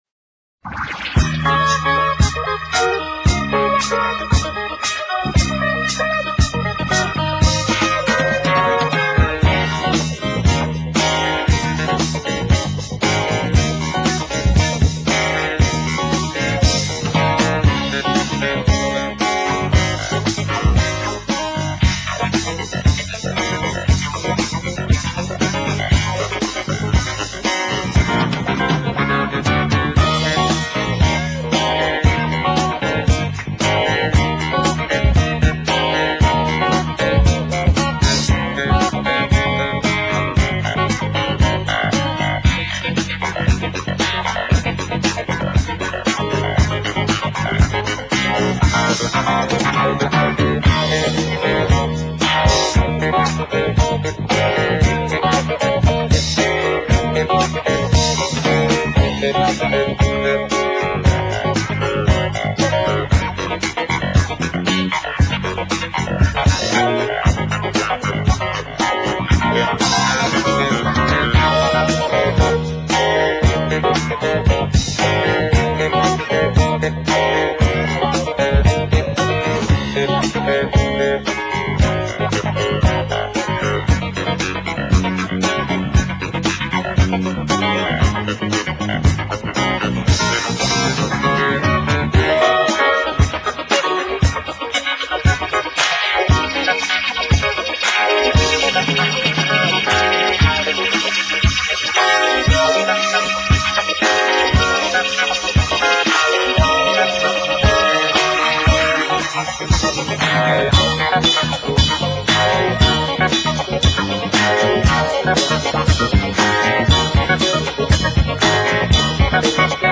not just in Funk